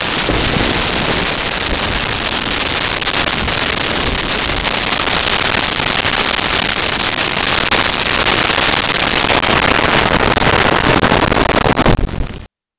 Thunder 1
Thunder_1.wav